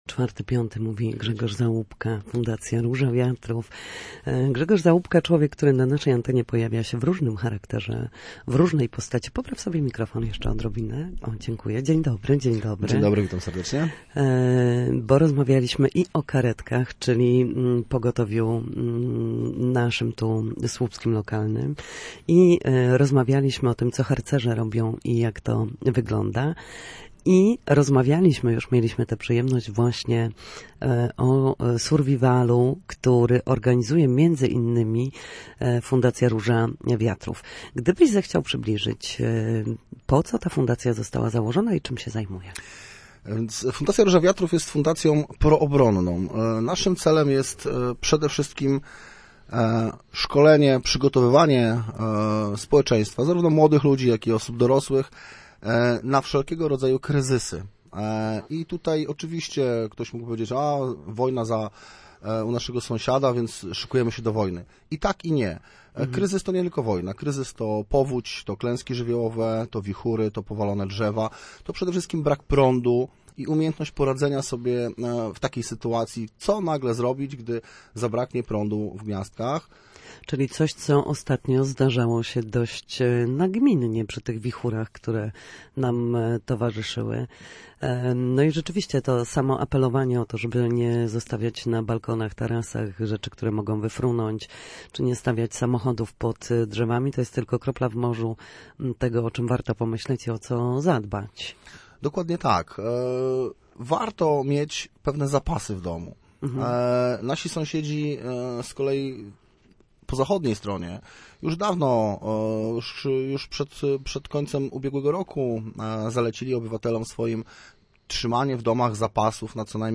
Podczas rozmowy zachęcał do udziału w szkoleniach, które w prosty sposób pomogą uniknąć kłopotów, zwrócą uwagę na cyberbezpieczeństwo, nauczą też i pokażą, co zabrać ze sobą, co włożyć do plecaka bezpieczeństwa w sytuacji zagrożenia.